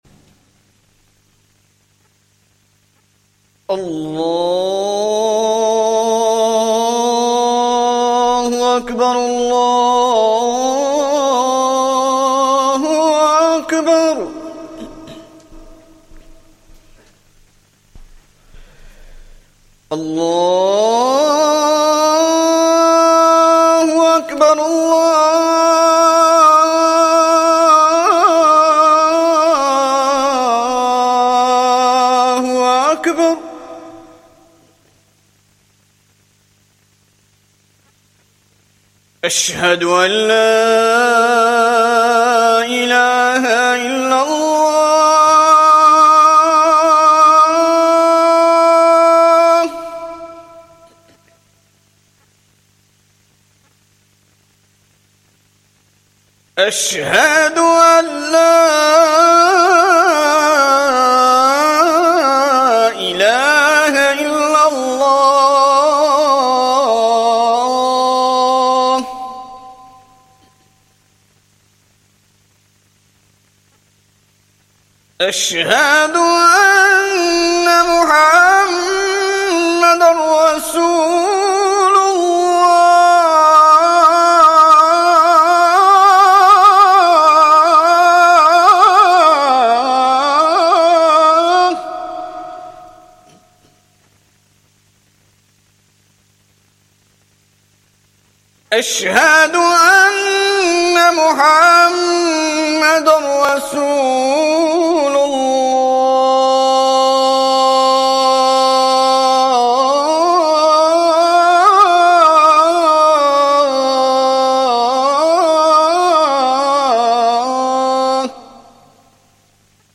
Esha Talk & Jammat